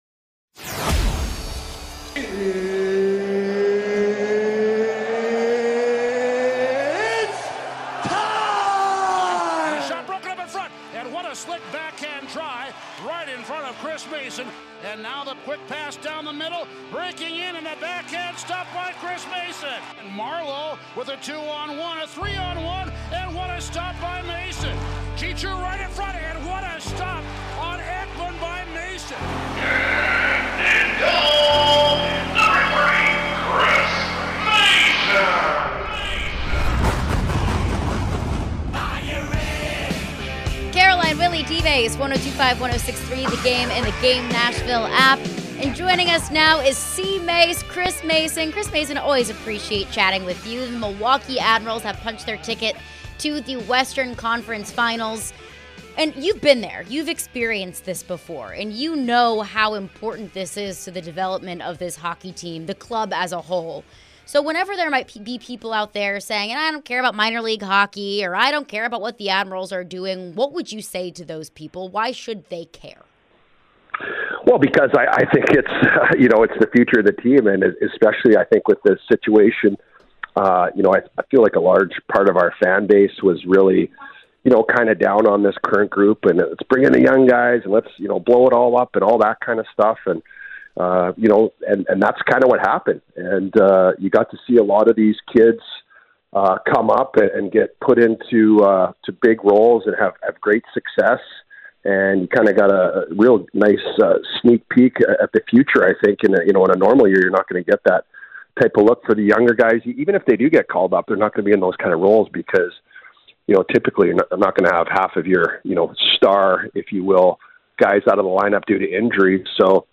Chris Mason Interview (5-22-23)